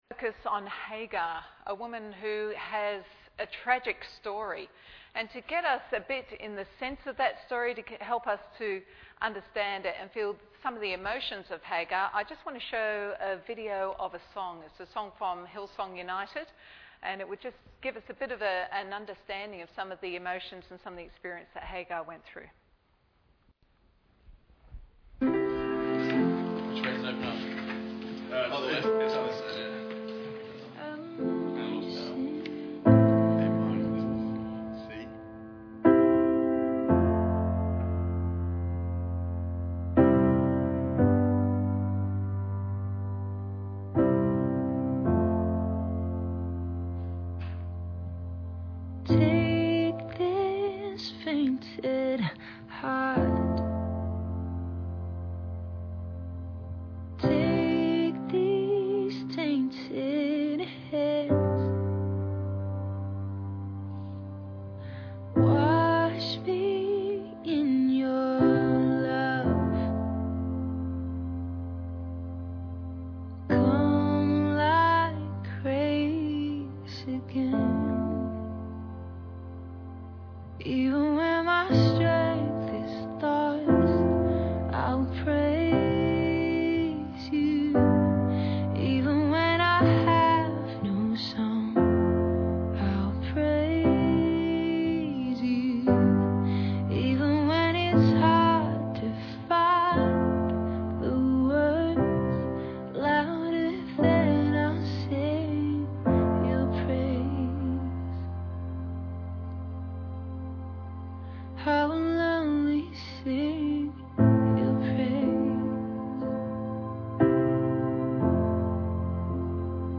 Bible Text: Genesis 16 | Preacher: